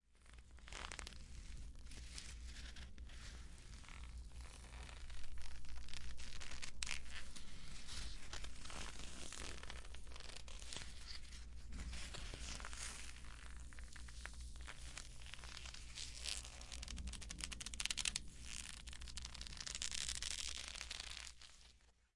音频1 " 14 Mov Cuervos
描述：Foley Final Audio1 2018